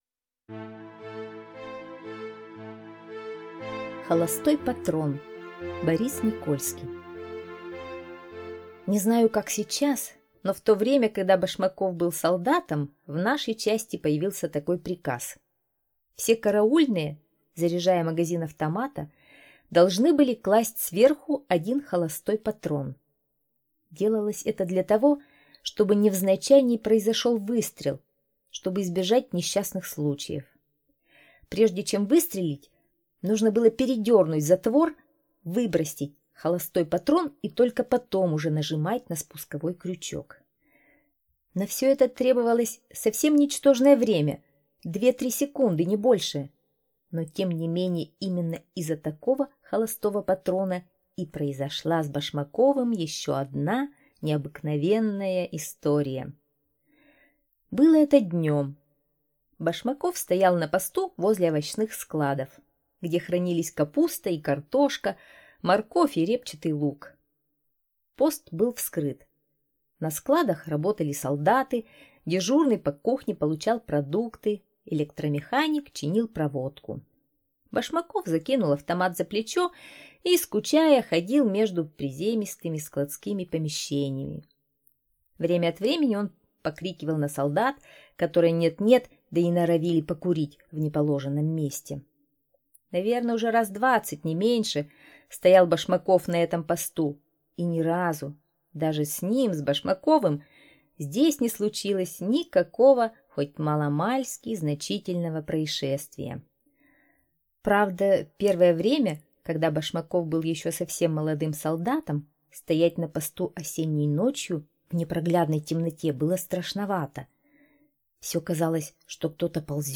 Аудиорассказ «Холостой патрон»